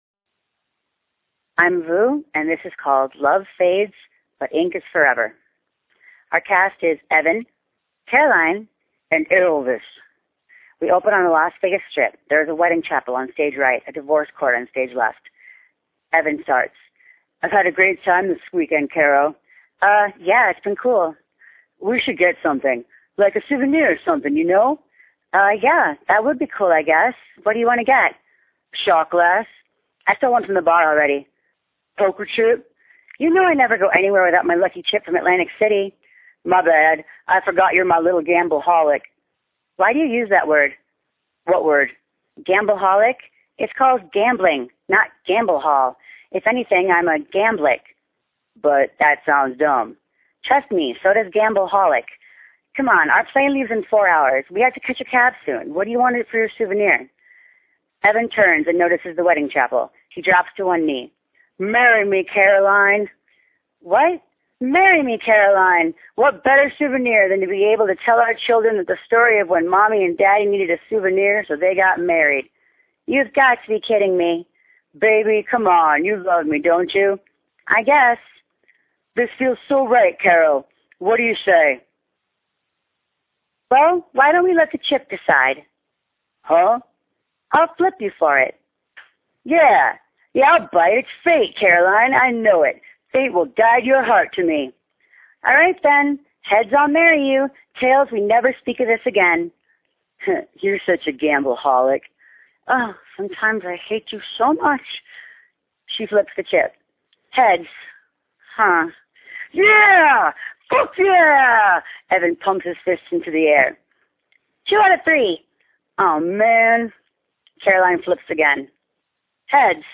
The MP3 file of me performing my play.